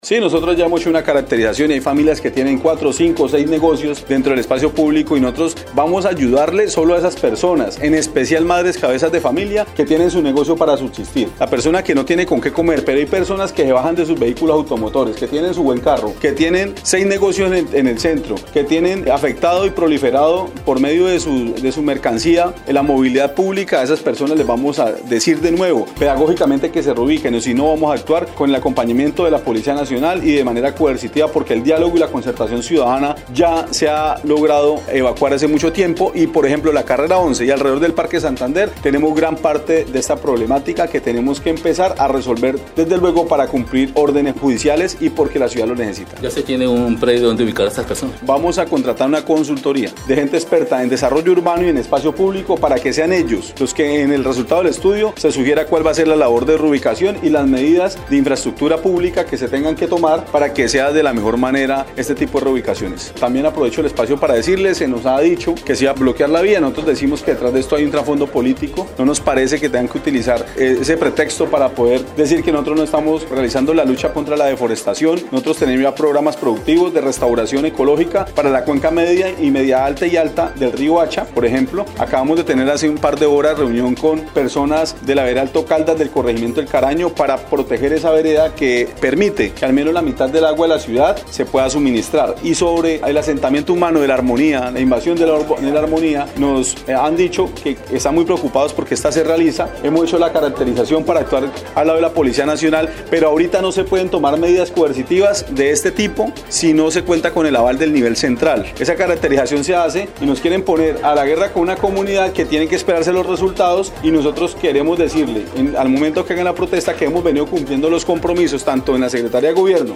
El mandatario de los florencianos explicó que, tras realizar una caracterización, se han detectado familias hasta con seis negocios que afectan la movilidad, mismas que mediante la pedagogía tendrán que reubicarse.
01_ALCALDE_MONSALVE_ASCANIO_ESPACIO.mp3